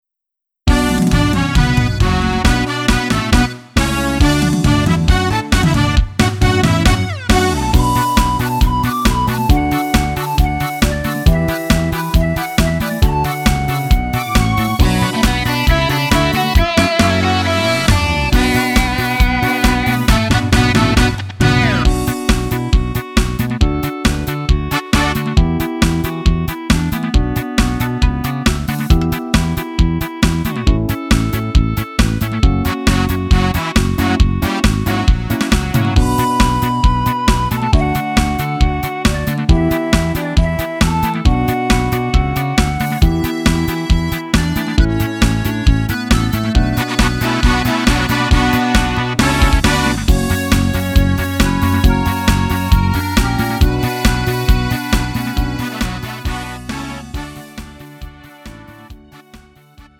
음정 -1키 3:19
장르 구분 Lite MR